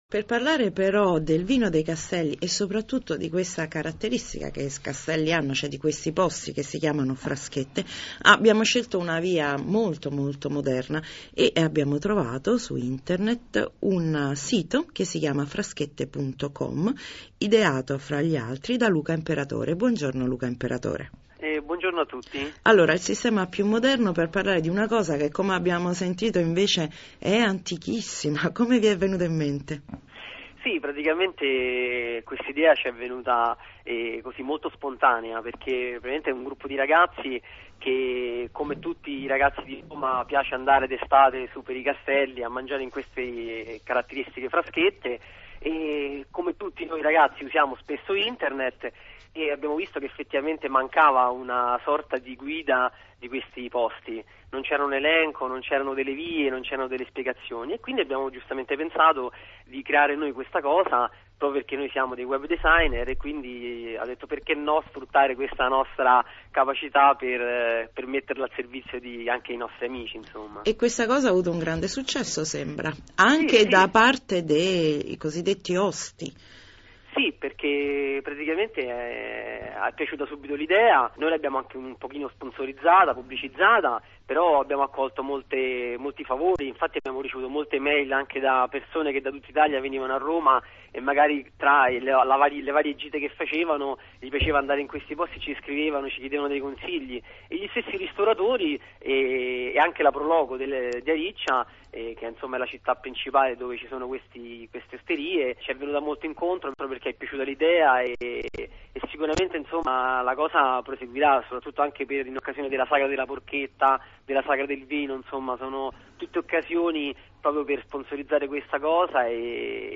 Intervistati da Radio Rai International nel programma “Taccuino Italiano” per una puntata dedicata ai Castelli Romani (
intervista_RAI.mp3